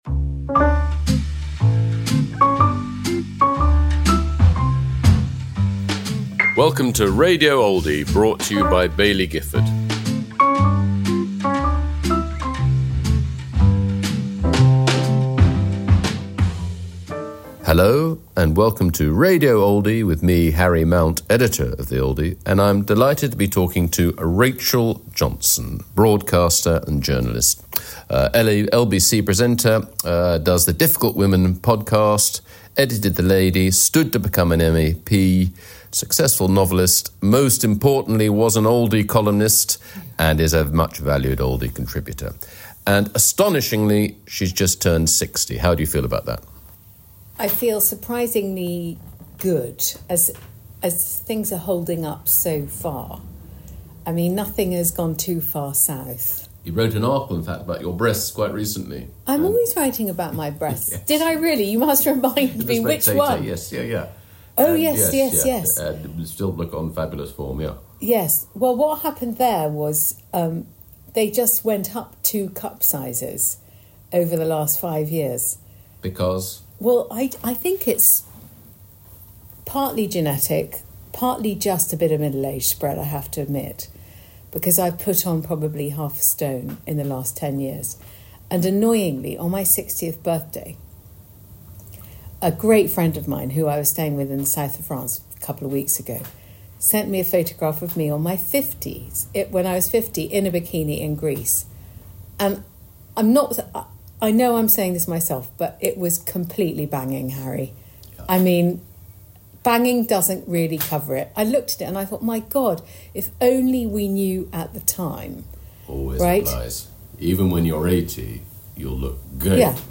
Rachel Johnson in conversation with Harry Mount